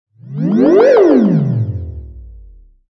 Short-sci-fi-sweep-sound-effect.mp3